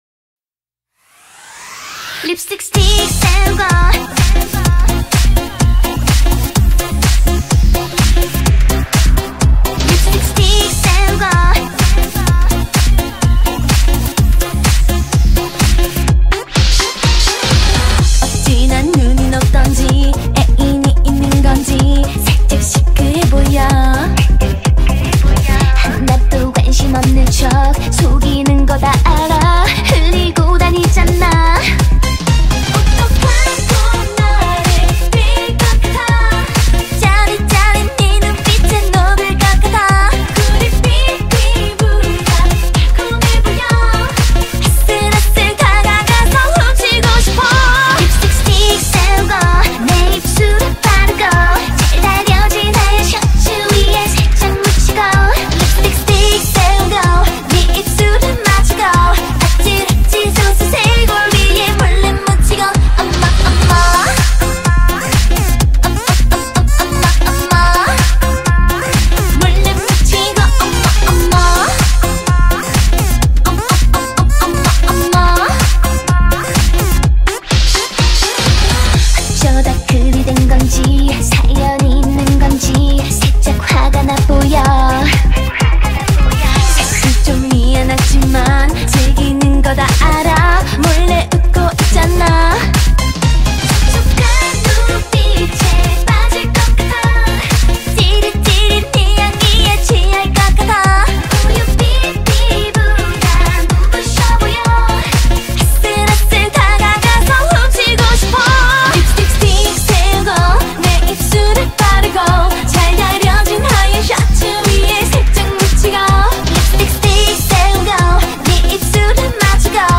BPM126
Comments[K-POP]